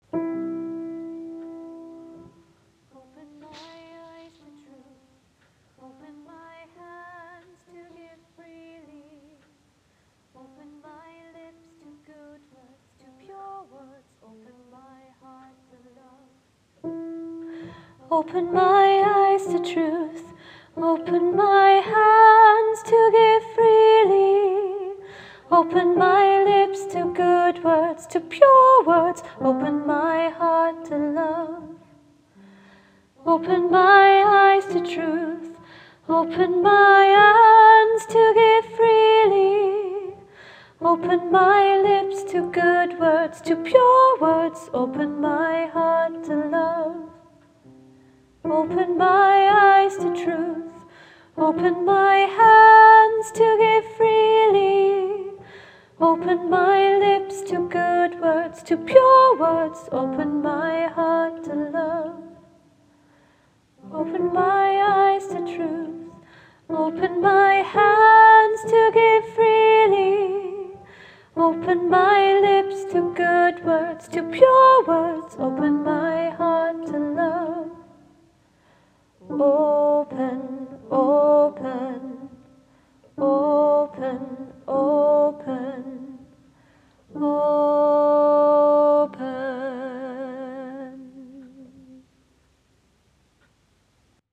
Open-Soprano-Lead - Three Valleys Gospel Choir